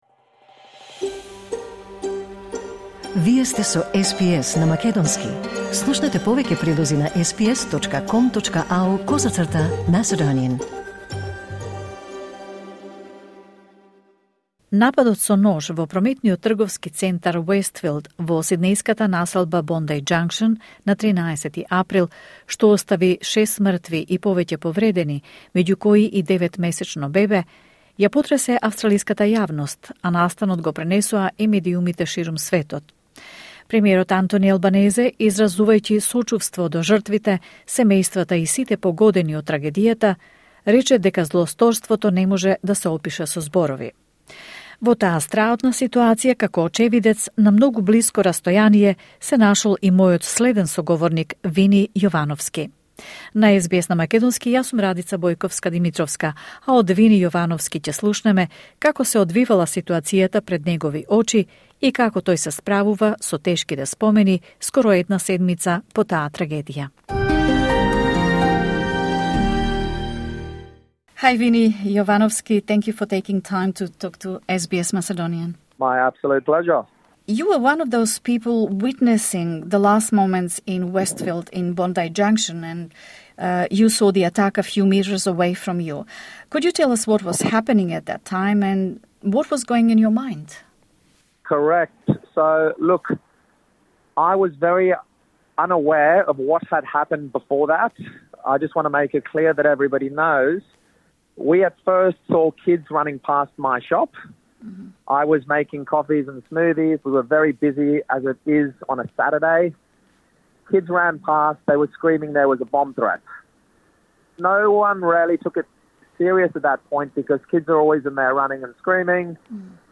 вели во разговор со СБС на македонски